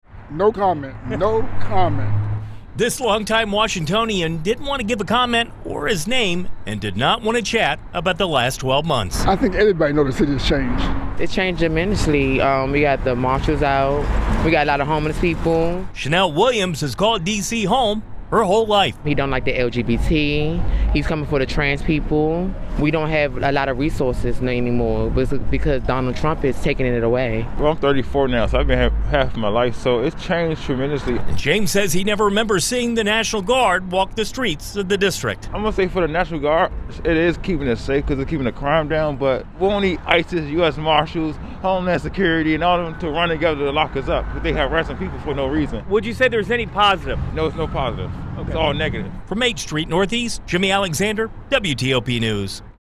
WTOP spoke with District residents walking 4th and H St NE, asking them how D.C. has changed during the first year of Trump’s second term.